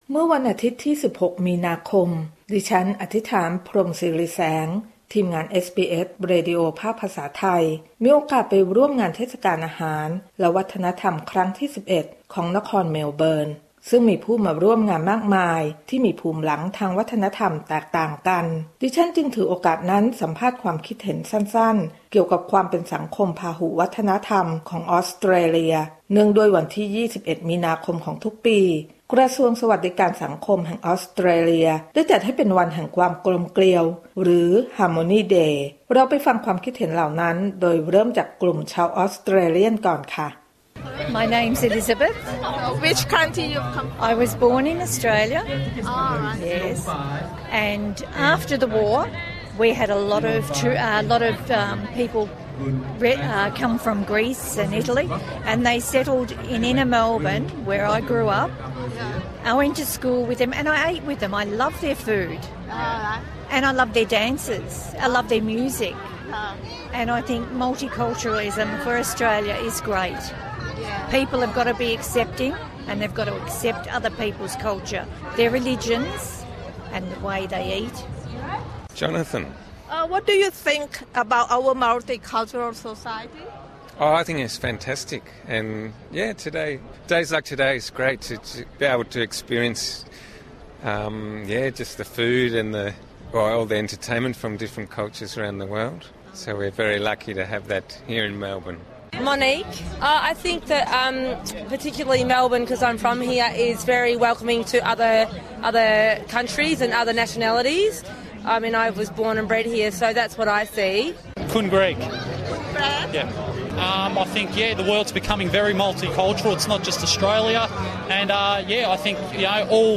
To celebrate Harmony Day, the Thai program also gathered some vox pop from the Thai cultural events relating to how people from different cultural backgrounds can live together in harmony.